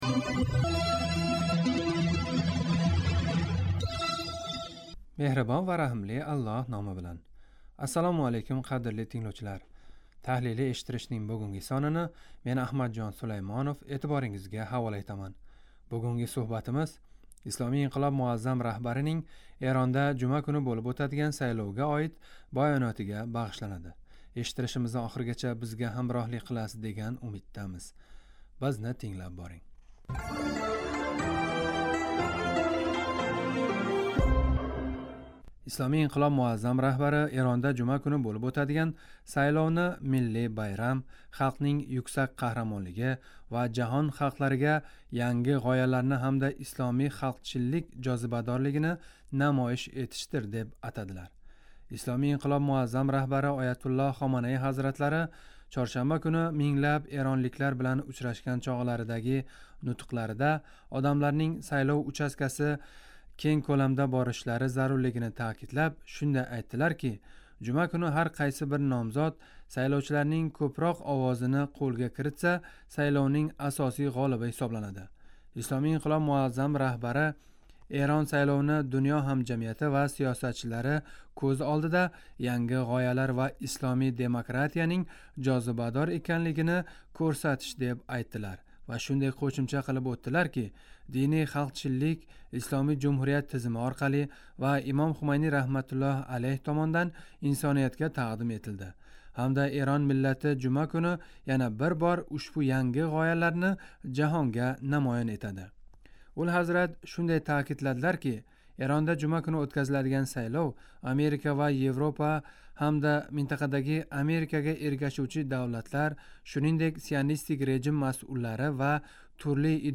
Таҳлилий эшиттиришнинг бугунги сонини эътиборингизга ҳавола этаман.